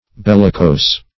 Bellicose \Bel"li*cose`\, a. [L. bellicosus, fr. bellicus of